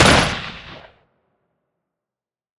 FX 5 [ bang ].wav